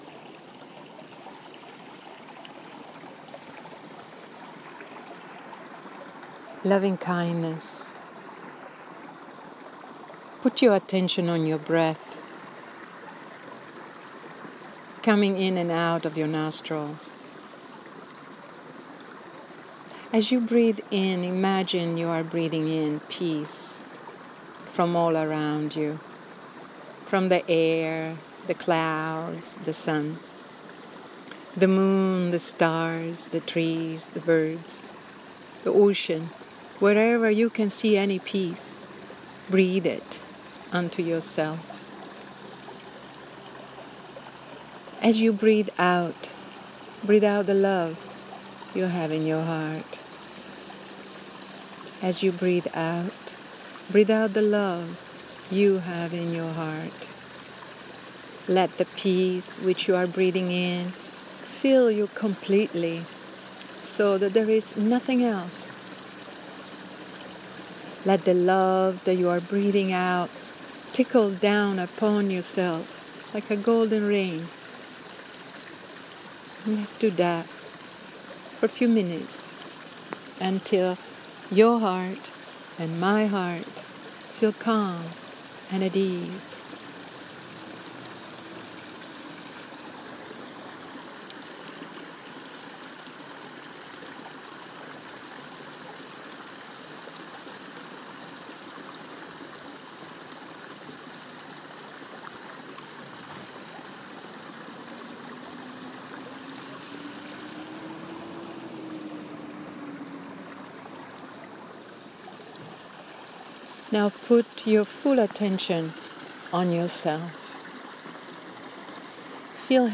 LovingKindness Meditation (audio only):